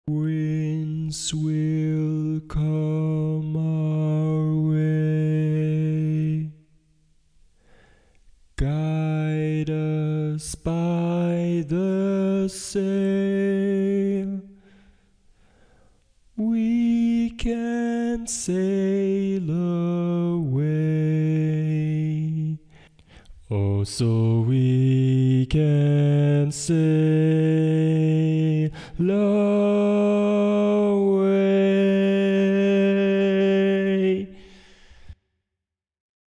Key written in: E♭ Major
Type: Barbershop
Comments: Original tag, ballad-style barbershop
Each recording below is single part only.